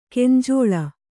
♪ kenjōḷa